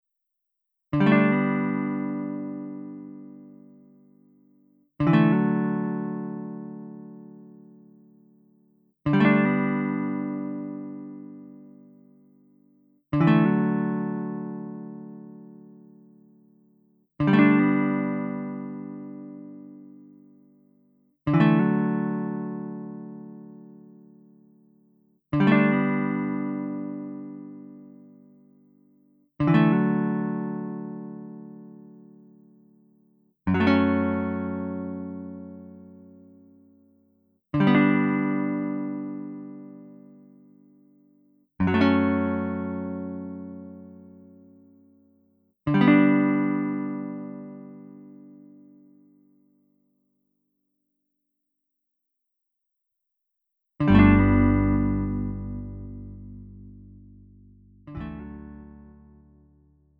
음정 원키 4:31
장르 구분 Lite MR